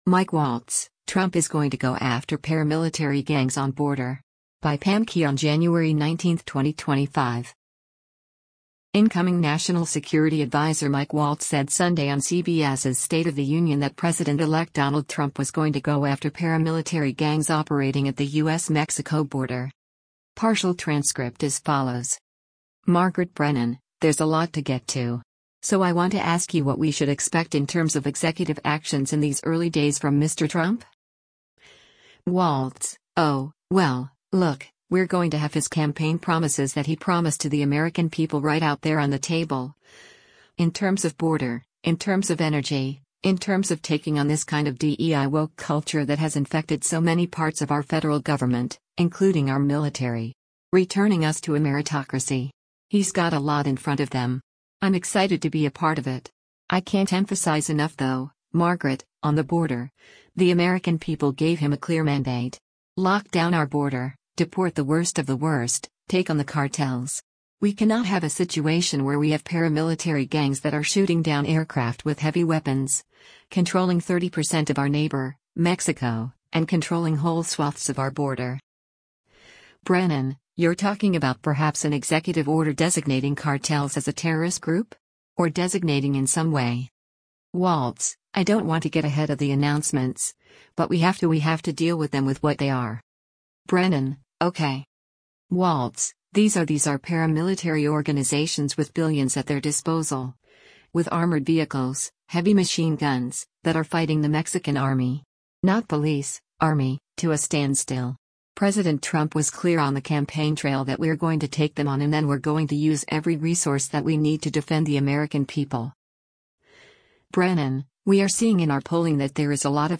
Incoming national security adviser Mike Waltz said Sunday on CBS’s “State of the Union” that President-elect Donald Trump was “going to go after” paramilitary gangs operating at the U.S.-Mexico border.